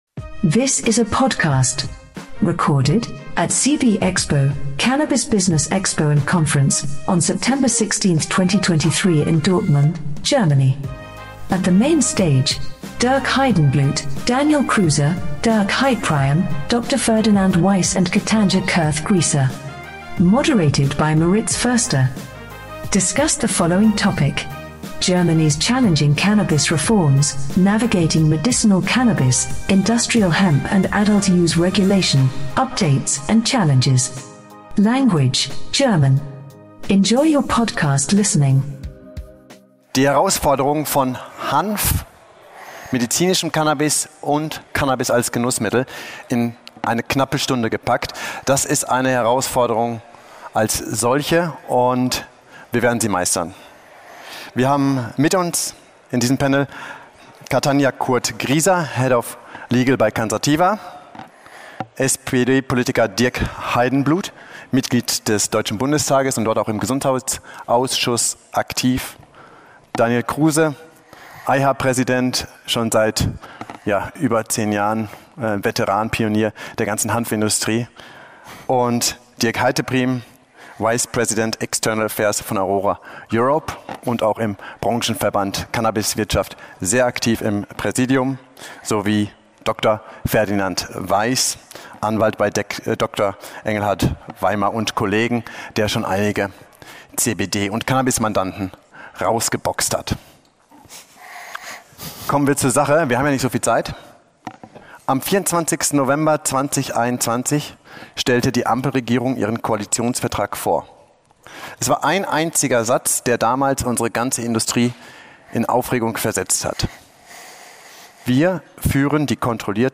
How industry and legislators finally leave the status quo behind will be discussed in this panel discussion.